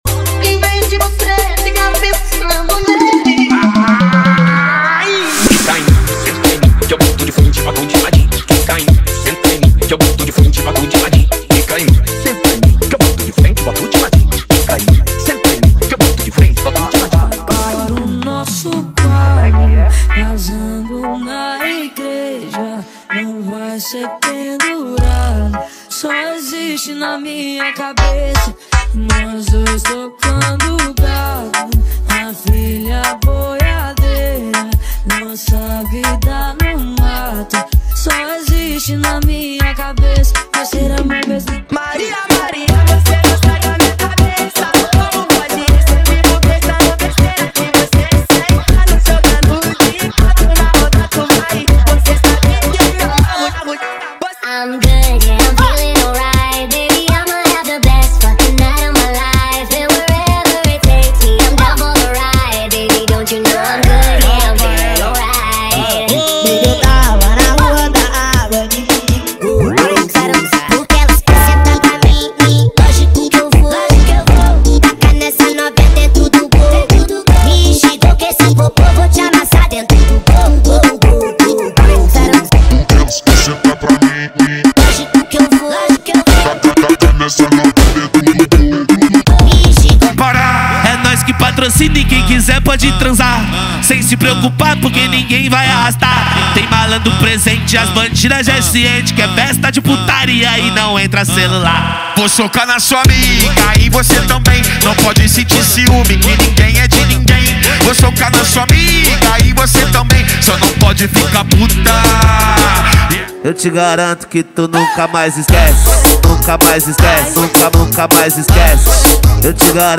• Brega Funk = 50 Músicas
• Sem Vinhetas
• Em Alta Qualidade